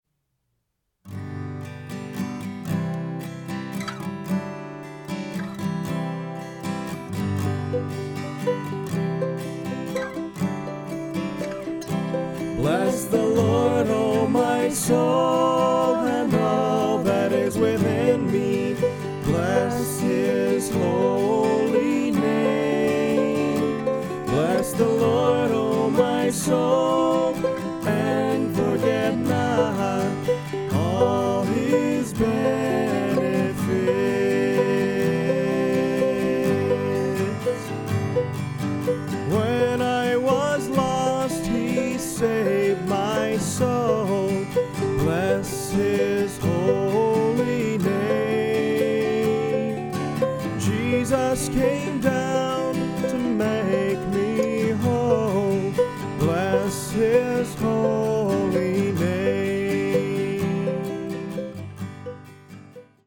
on guitar
on ukulele